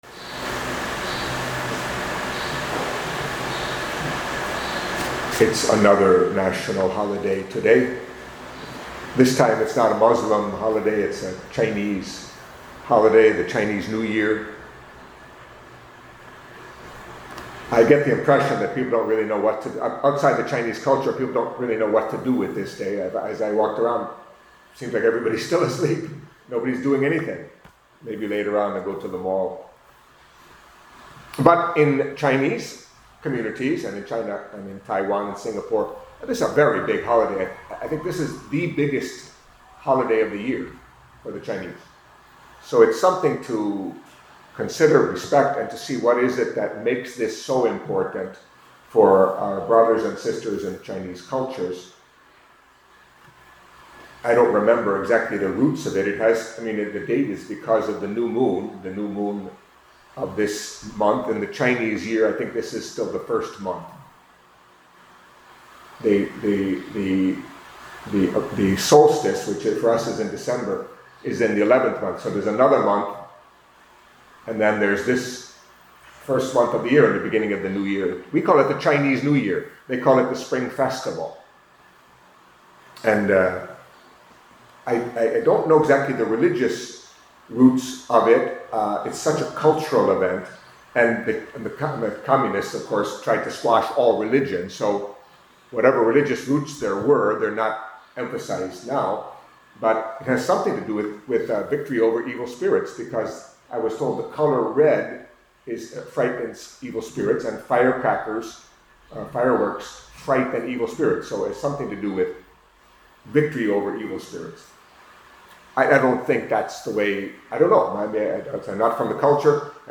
Catholic Mass homily for Wednesday of the Third Week in Ordinary Time